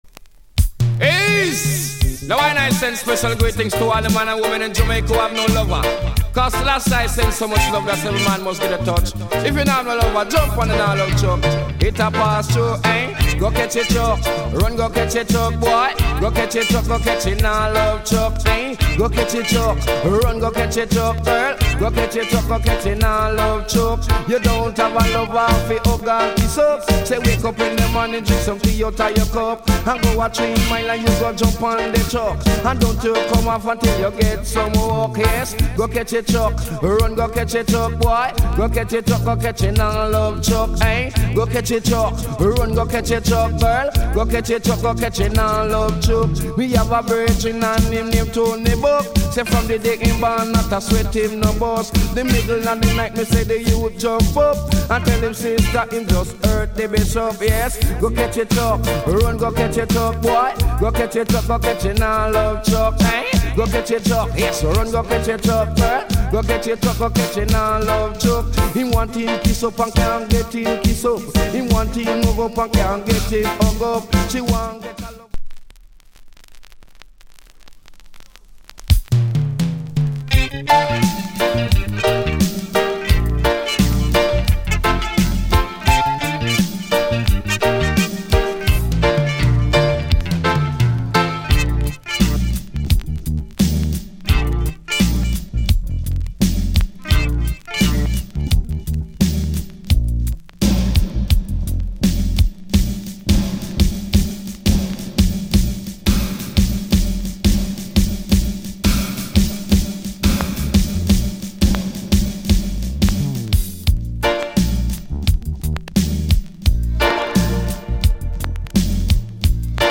* Mid 80's Tuff DJ Tune パンチラインがGood!!